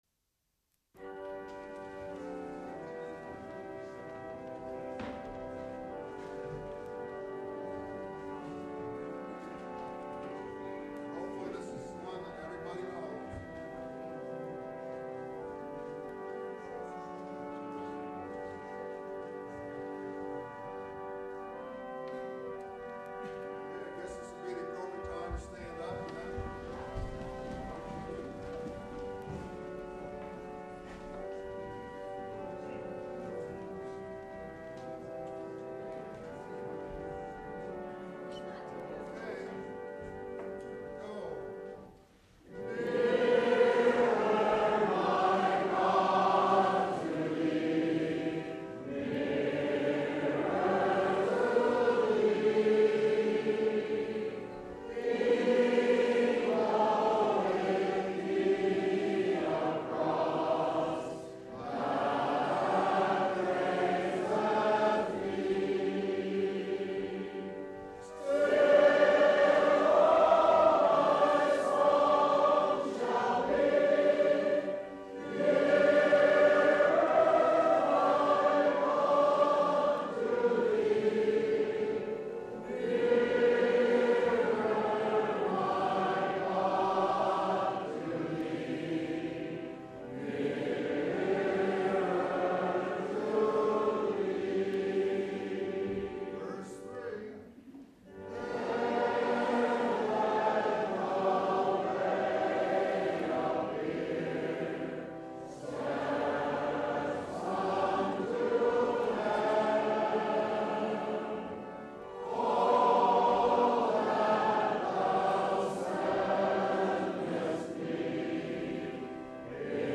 for Woodwind Quintet (1988)